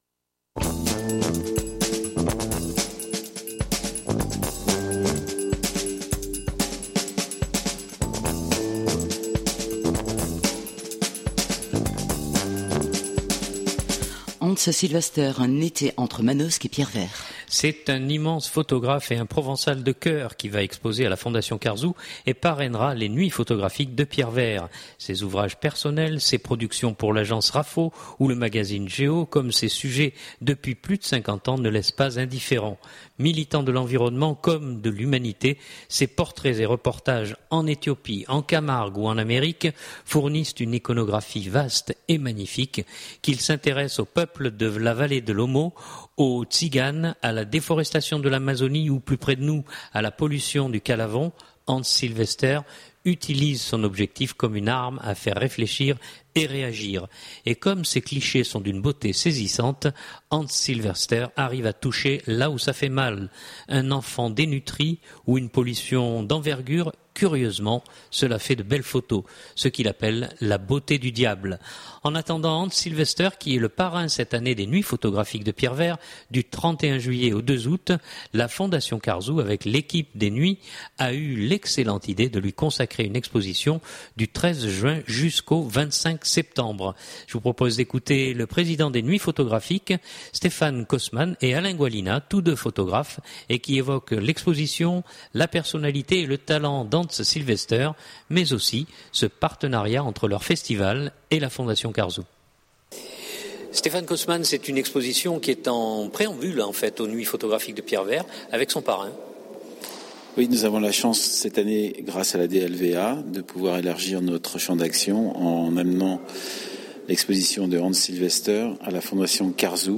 tous deux photographes et qui évoquent l’exposition, la personnalité et le talent d’Hans Silvester mais aussi ce partenariat entre leur festival et la Fondation Carzou.